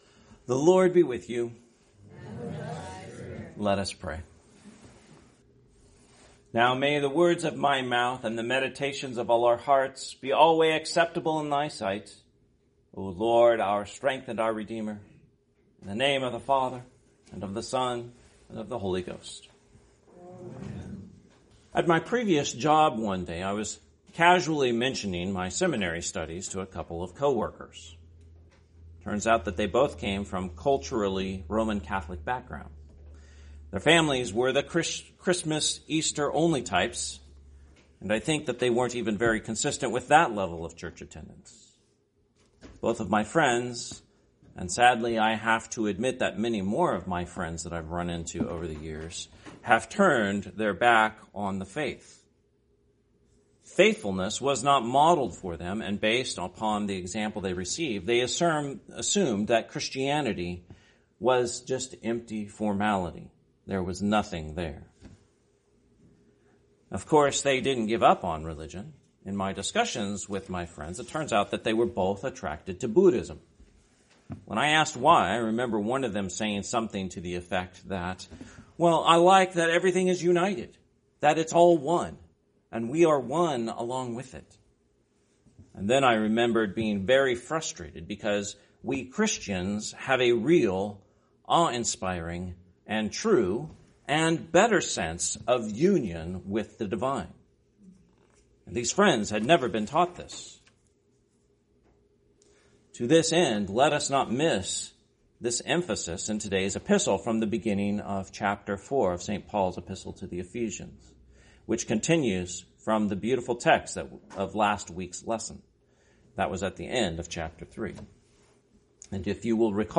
Sermon, 17th Sunday after Trinity, 2025 – Christ the King Anglican Church